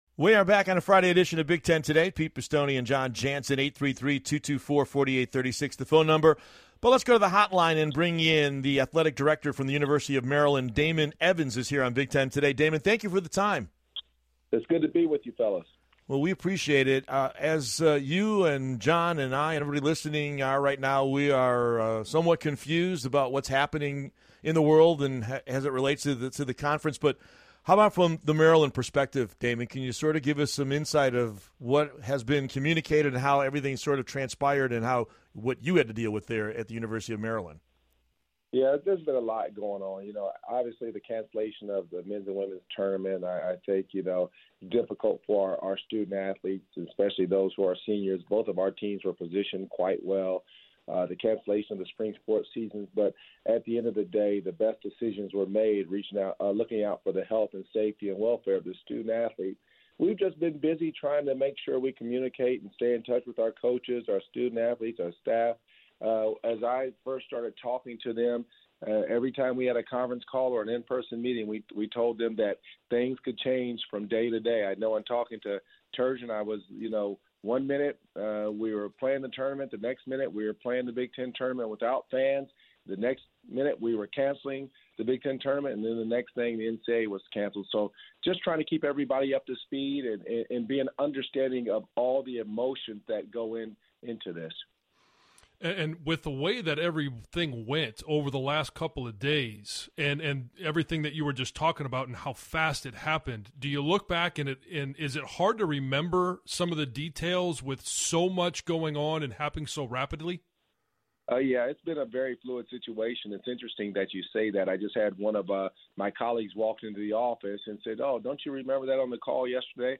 Interview c/o Sirius XM.